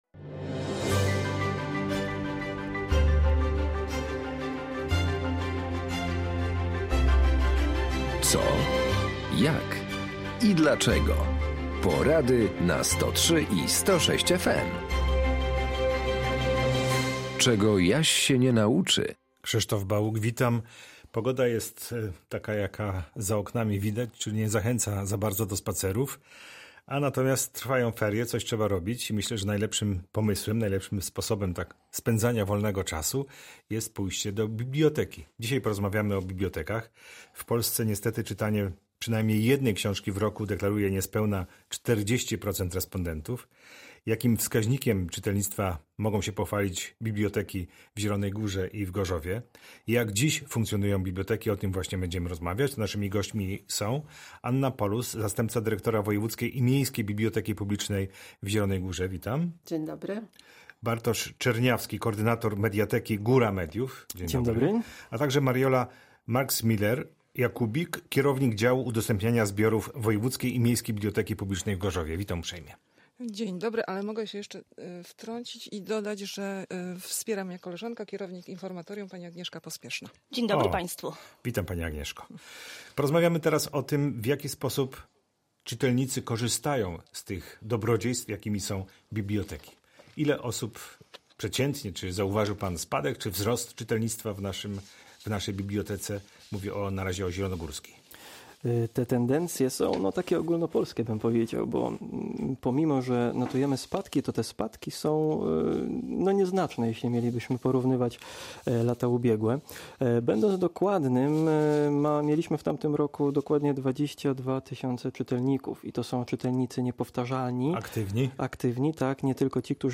Goście audycji